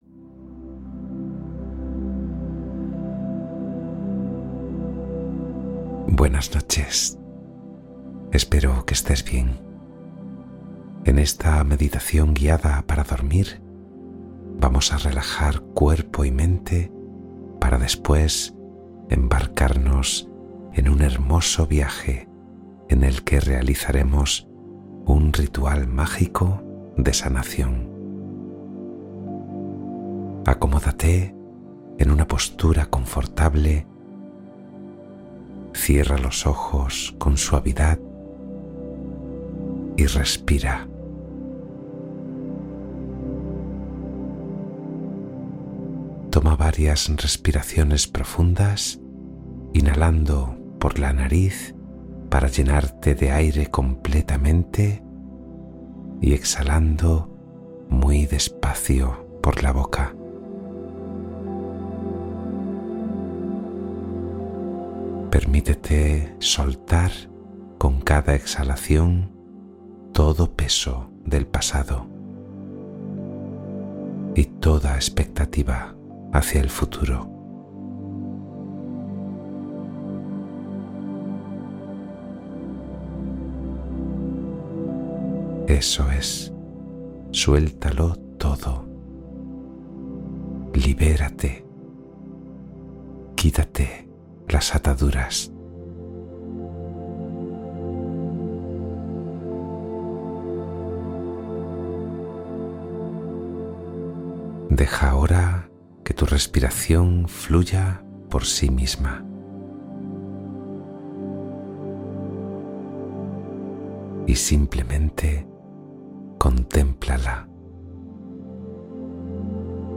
Meditación relajante para un sueño profundo y reparador